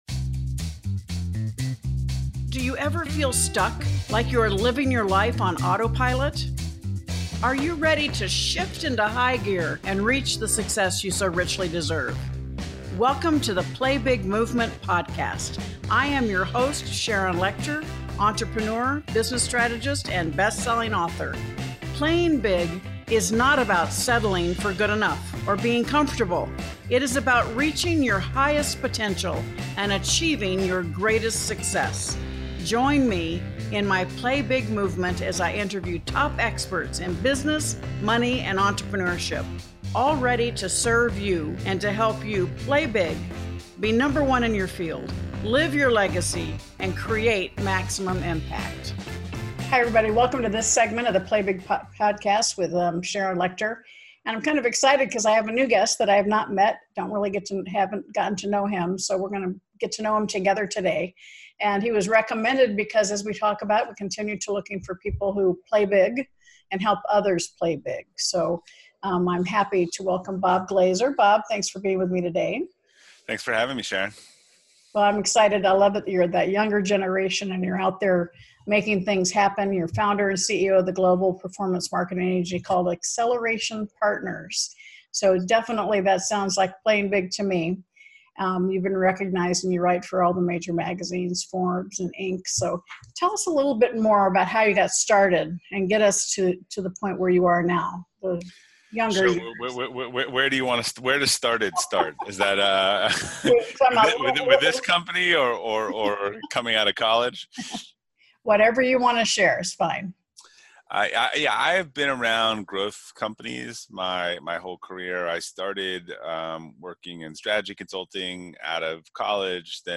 Listen to our conversation for strategies on how to clarify your company’s vision, and achieve your growth goals.